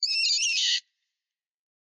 PixelPerfectionCE/assets/minecraft/sounds/mob/rabbit/hurt3.ogg at mc116